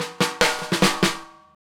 Twisting 2Nite Drumz Fill 1.wav